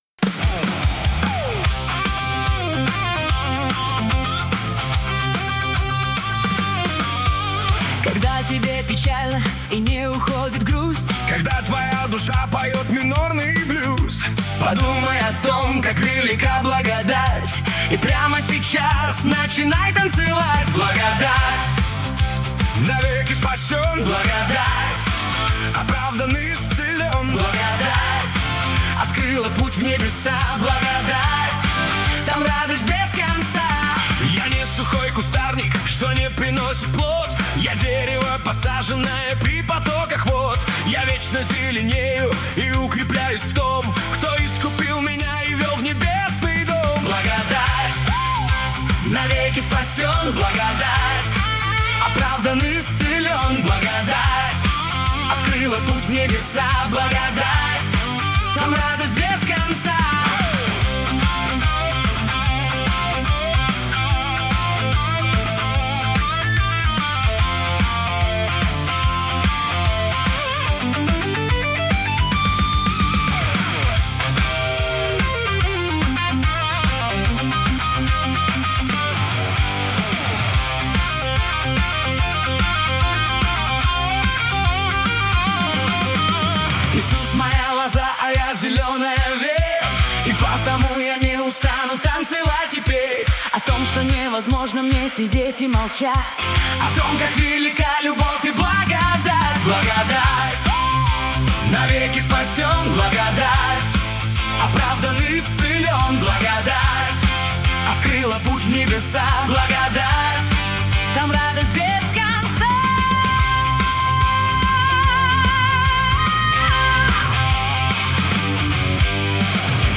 песня ai
15 просмотров 46 прослушиваний 8 скачиваний BPM: 146